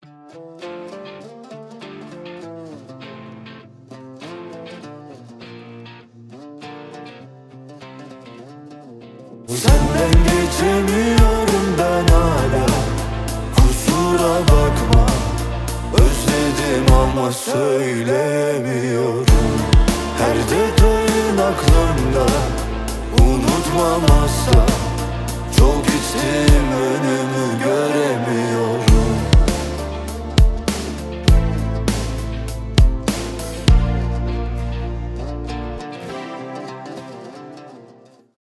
Категория: Рок рингтоныЗарубежные рингтоны